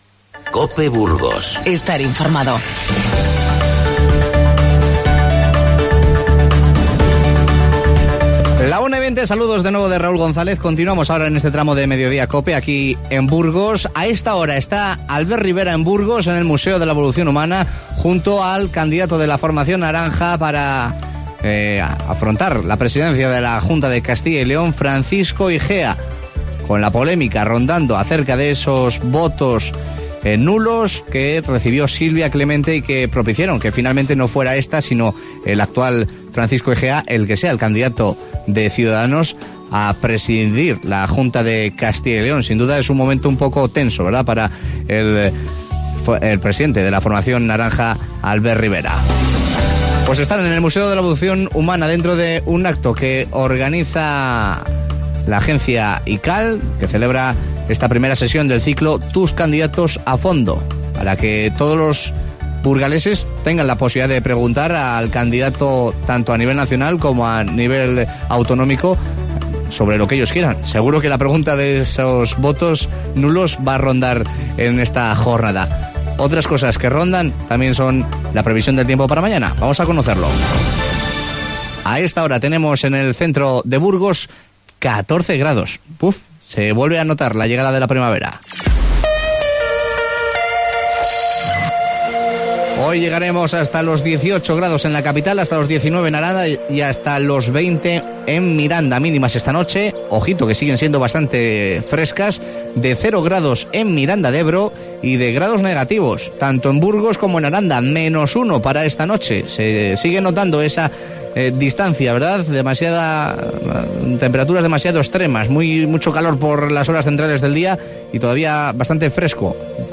Hoy 22 de marzo es el Día Mundial del Agua. Hemos hablado con los miembros de la ong Amycos, que trata de hacer llegar agua potable a zonas de Bolivia y Nicaragua y en la Plaza Santo Domingo de Guzmán están tratando de concienciar a los burgaleses para que hagan un uso responsable del agua.